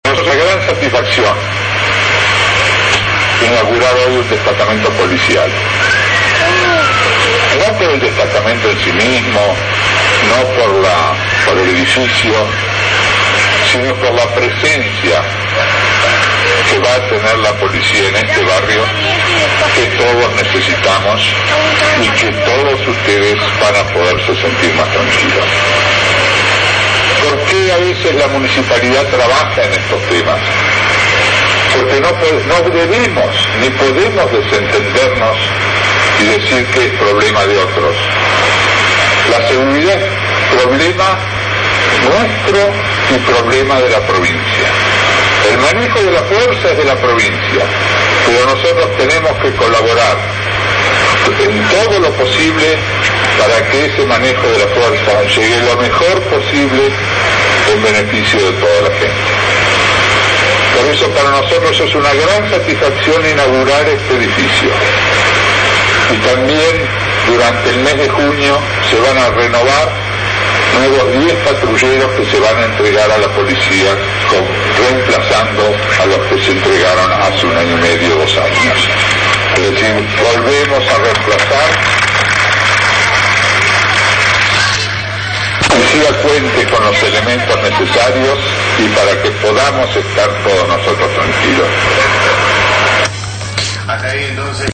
Audio:Palabras del Intendente de Tigre Ricaro Ubieto
El intendente de Tigre Ricardo Ubieto dejó inaugurado hoy el nuevo destacamento Policial en Troncos de Talar ubicado en Dardo Rocha y Nuestra Señora de Lujan.